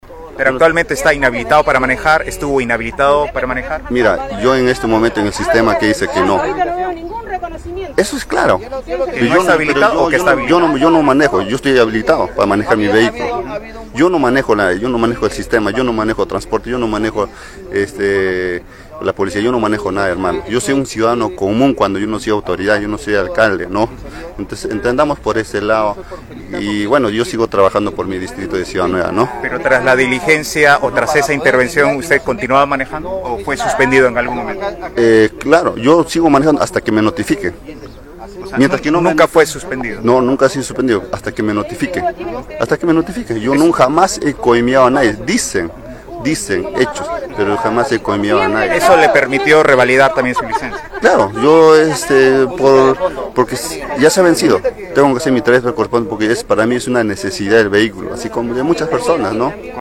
“Yo estoy habilitado para manejar mi vehículo, yo no manejo el sistema, yo no manejo transporte, yo no manejo la Policía, yo no manejo nada, yo soy un ciudadano común cuando no he sido alcalde”, dijo a Radio Uno al término de ceremonia en Ciudad Nueva.